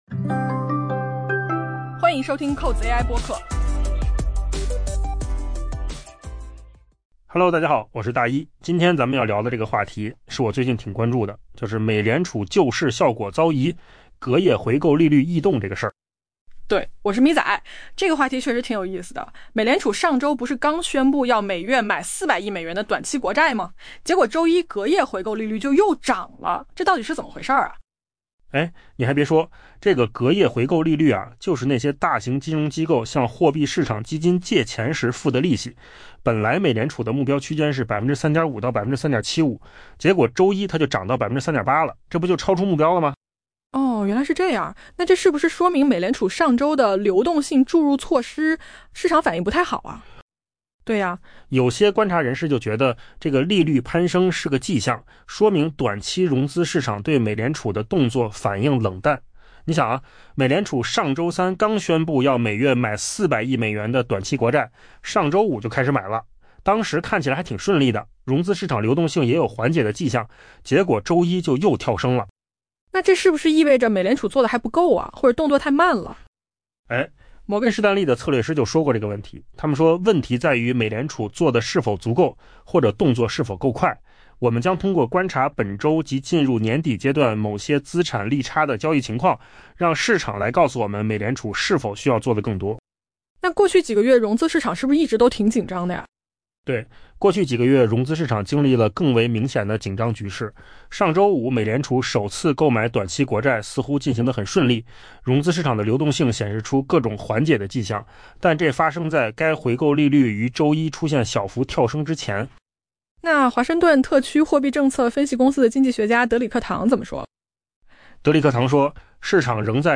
AI 播客：换个方式听新闻 下载 mp3 音频由扣子空间生成 尽管美联储上周尽力平息局势，但金融市场的一个关键角落再次出现了可能存在麻烦的迹象。